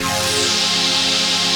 ATMOPAD11.wav